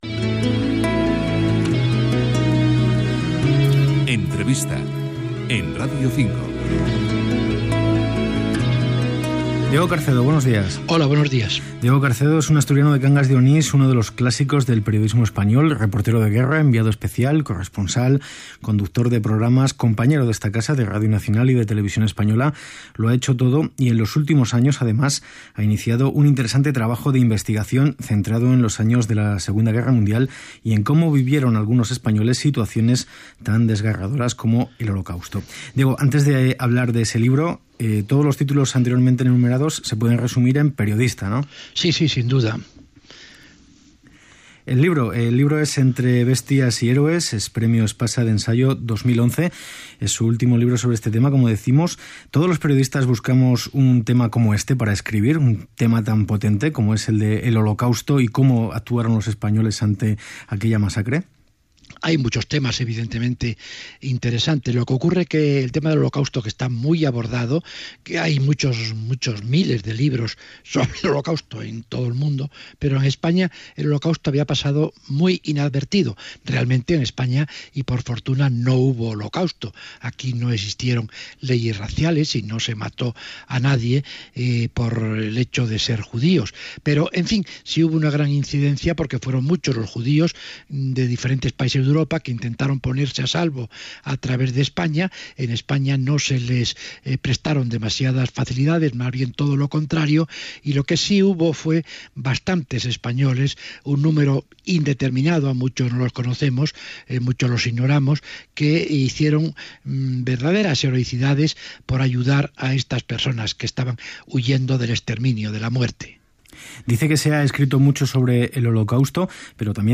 Entrevista (Radio 5)
Careta del programa, presentació i entrevista al periodista Diego Carcedo pel seu llibre "Un español frente al holocausto".